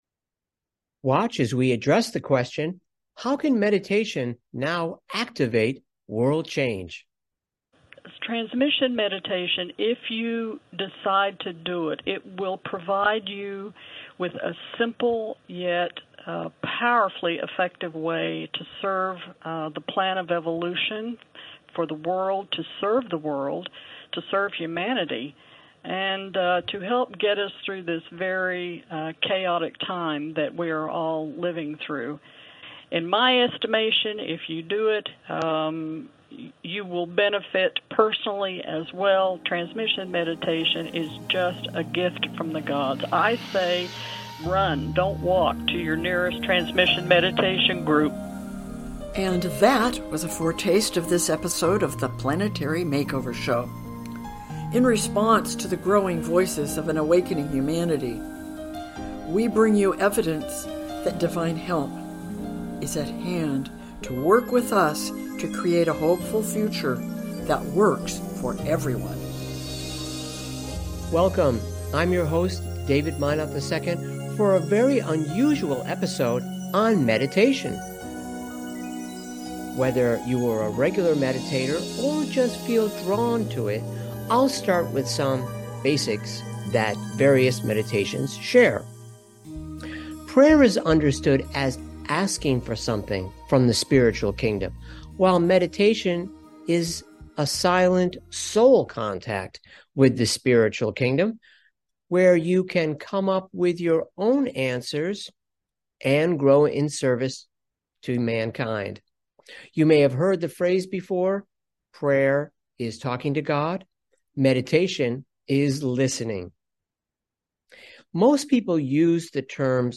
Talk Show Episode, Audio Podcast, Planetary MakeOver Show and How Can Meditation Now Activate World Change?